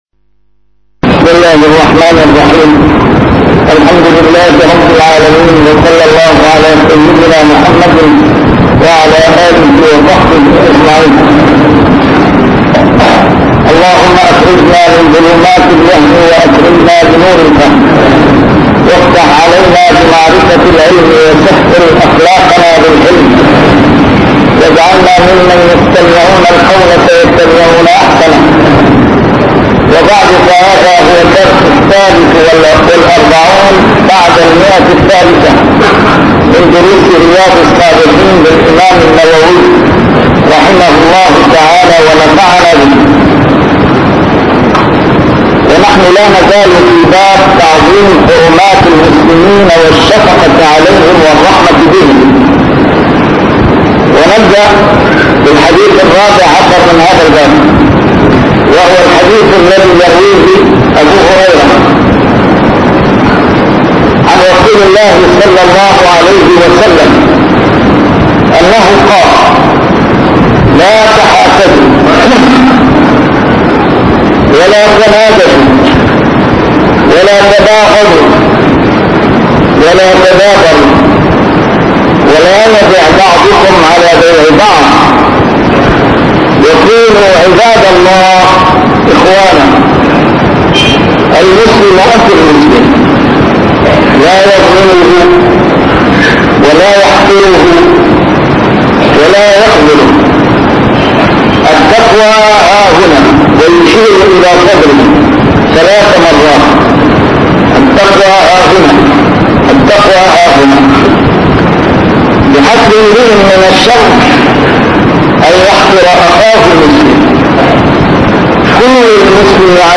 A MARTYR SCHOLAR: IMAM MUHAMMAD SAEED RAMADAN AL-BOUTI - الدروس العلمية - شرح كتاب رياض الصالحين - 343- شرح رياض الصالحين: تعظيم حرمات المسلمين